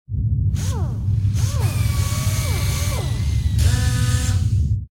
repair2.ogg